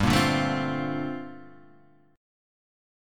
Gm11 chord {3 1 0 2 1 1} chord